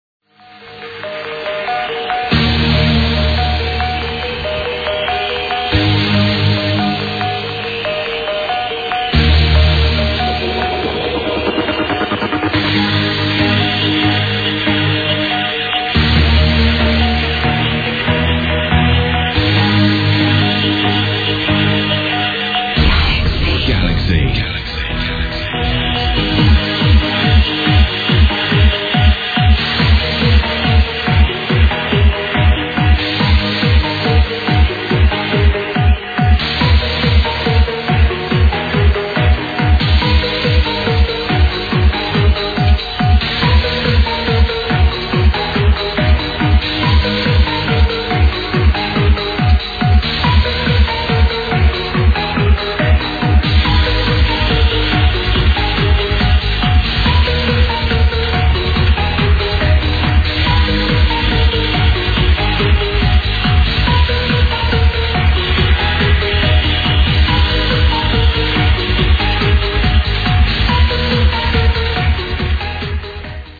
sorry for the shit quality sample.